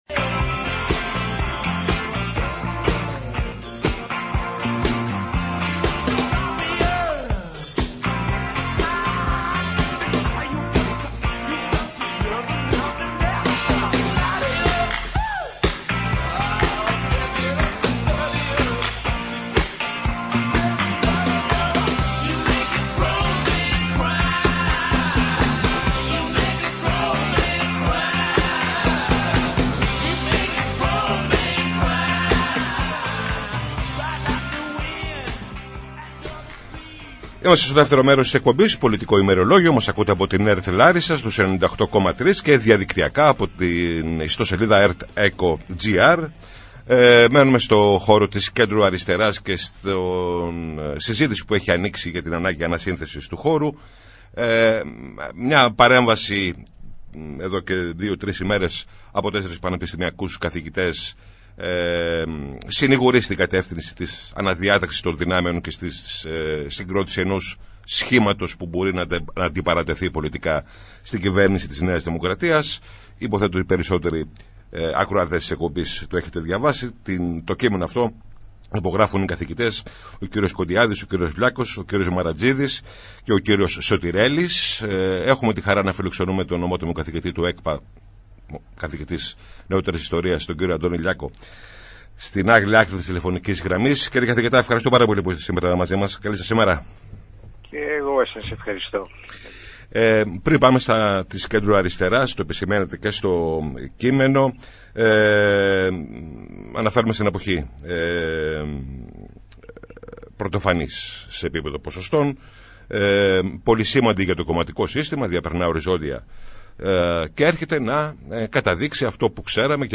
Καλεσμένος ο Αντώνης Λιάκος, ιστορικός και ομότιμος καθηγητής του Πανεπιστημίου Αθηνών | 28.06.2024